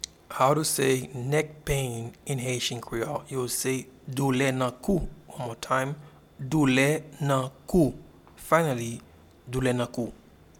Pronunciation and Transcript:
Neck-pain-in-Haitian-Creole-Doule-nan-kou.mp3